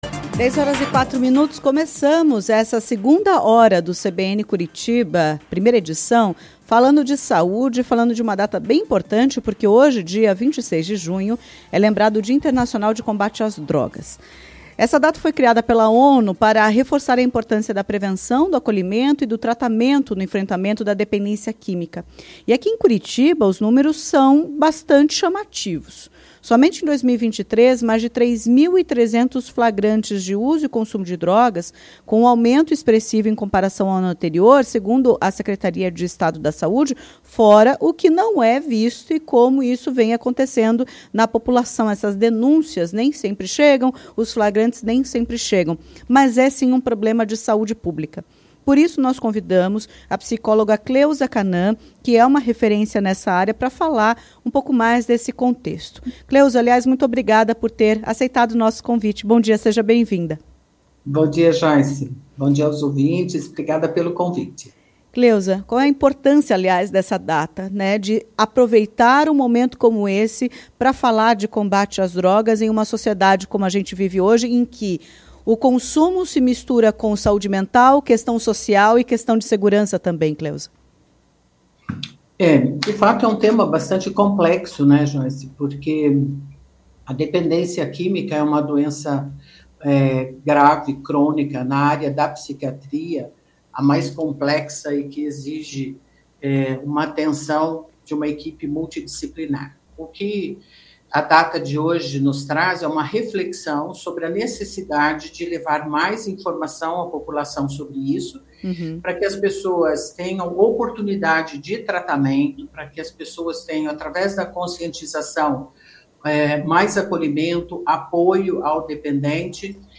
Em conversa à CBN Curitiba, ela destacou como a prevenção passa pelo exemplo. Isso acontece em relação aos comportamentos dos pais, o que é acompanhado de perto pelos jovens.